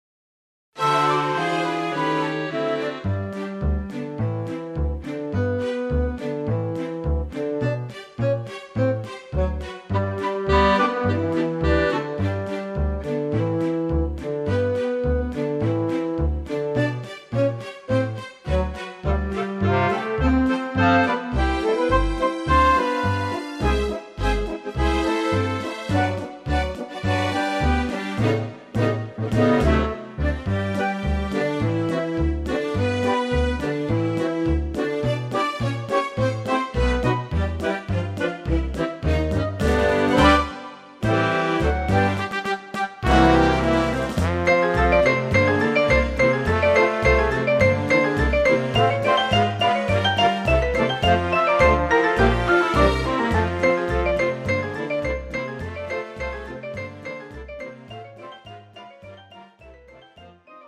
(뮤지컬) MR 반주입니다.